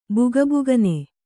♪ bugabugne